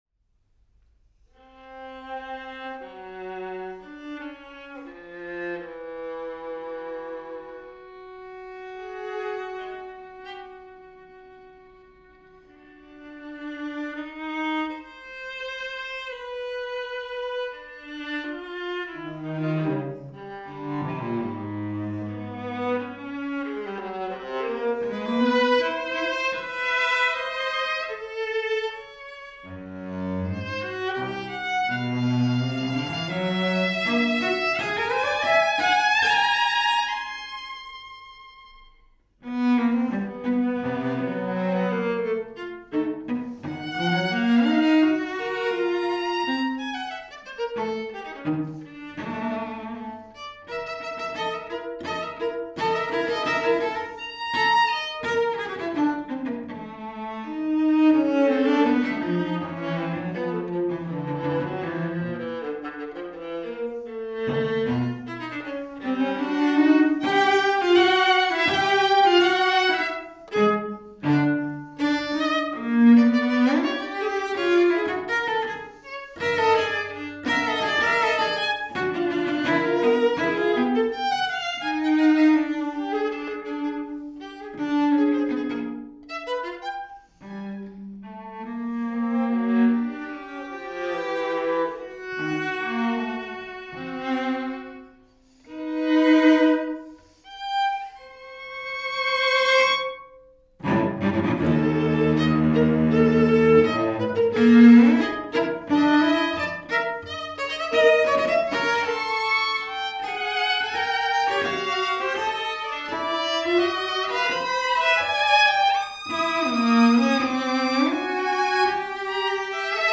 For viola and cello